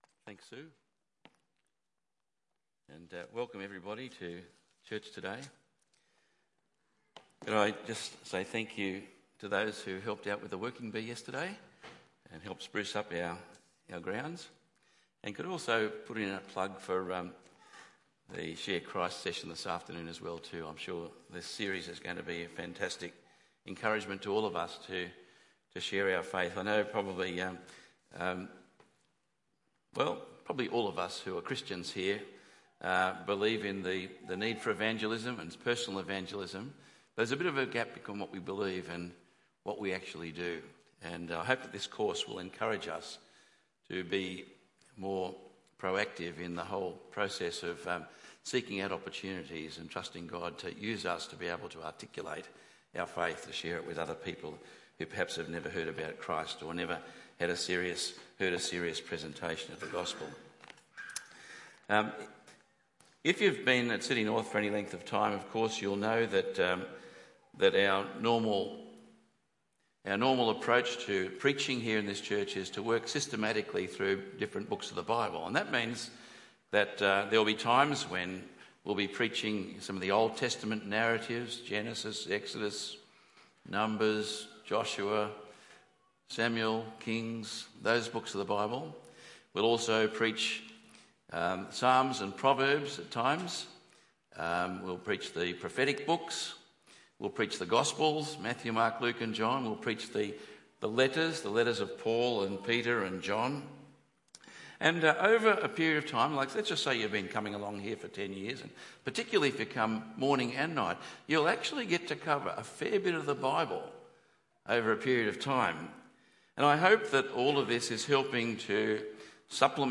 Malachi 1:1-5 Tagged with Sunday Morning